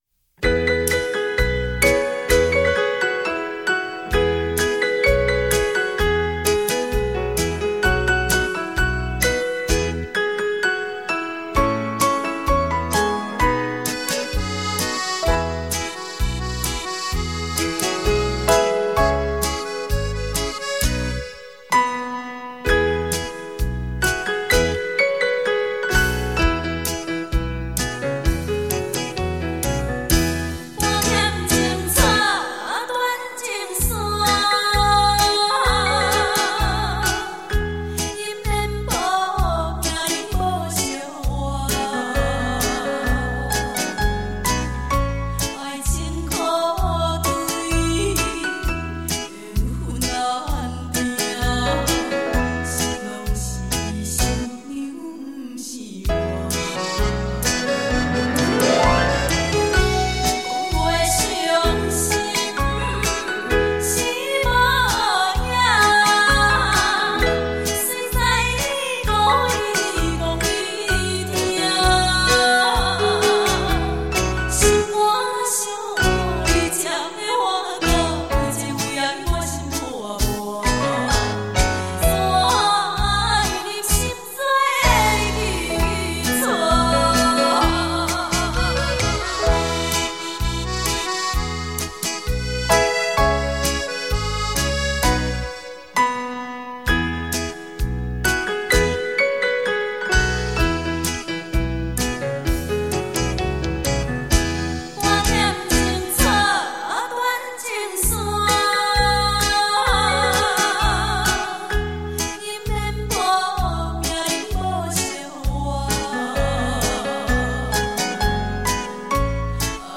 本音乐带采用美国最新调音器·音质保证